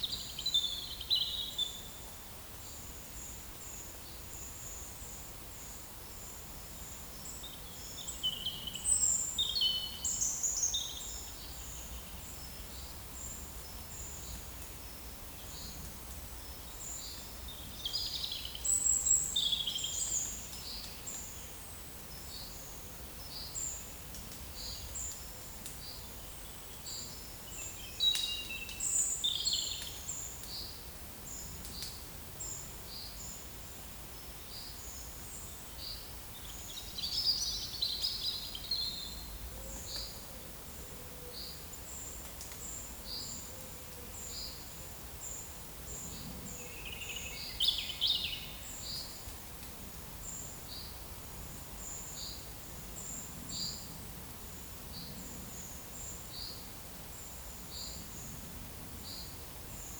Monitor PAM
909541 | Certhia familiaris 909540 | Certhia familiaris 909539 | Fringilla coelebs 909538 | Certhia brachydactyla
Regulus ignicapilla 909532 | Columba palumbus
Erithacus rubecula
Aegithalos caudatus